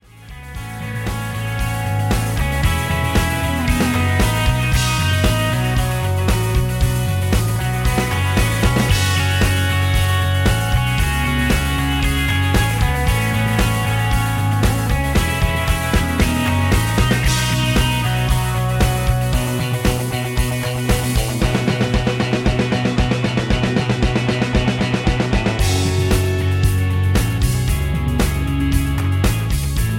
Without Backing Vocals